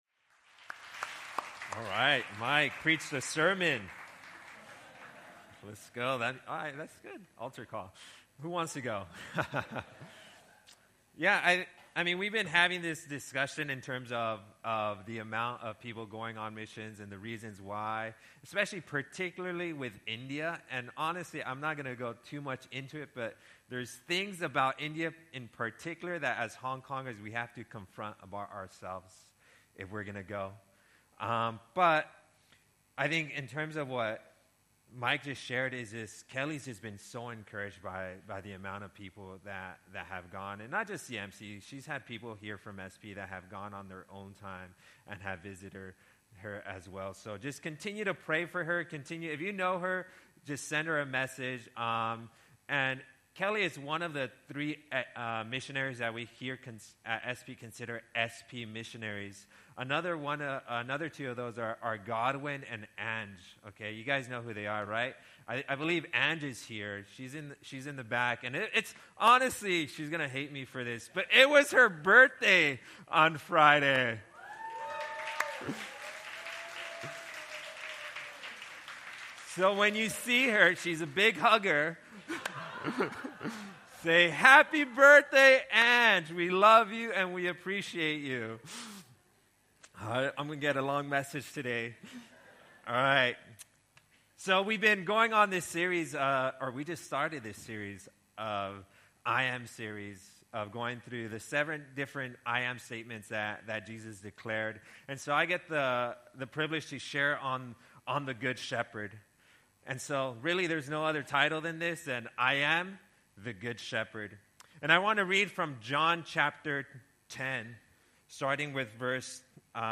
Sermons from Solomon's Porch Hong Kong.